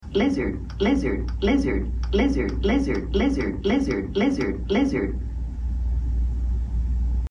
Lizard Meme Stuck Sound Effects Free Download